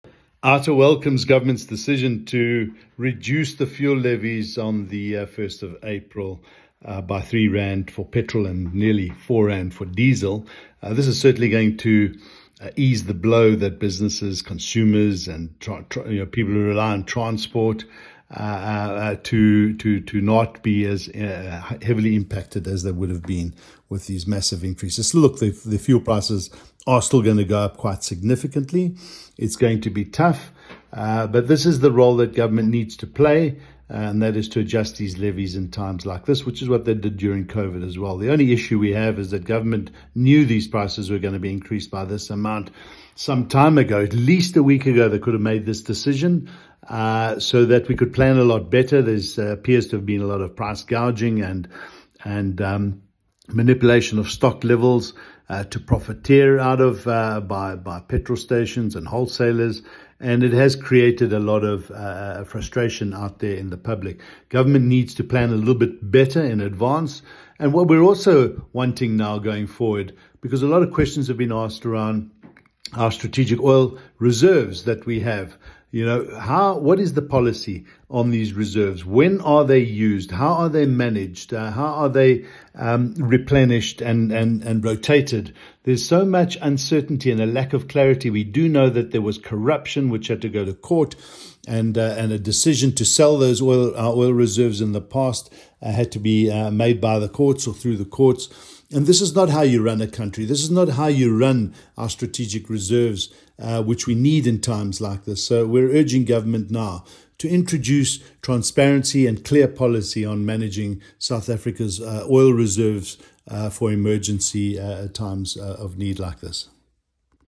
A voicenote